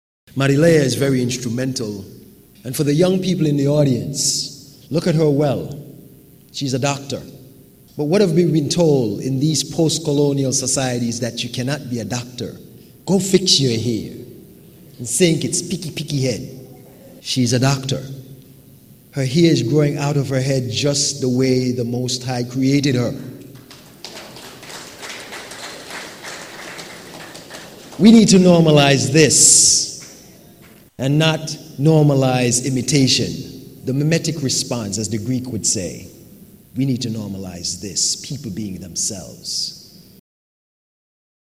The Prime Minister’s Independence Lecture Series was held at the St. Kitts Marriott Resort Ballroom on Wednesday, September 13th, 2022.